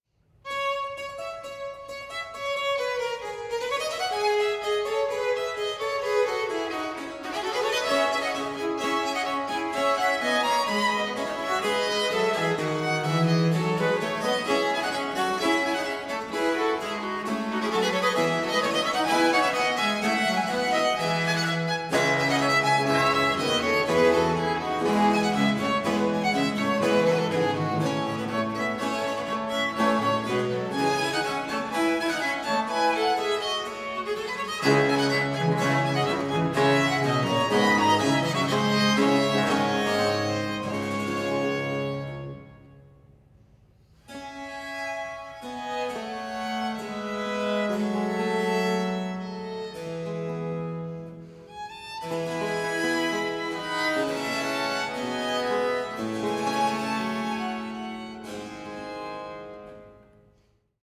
• Genres: Baroque, Chamber Music
Baroque string ensemble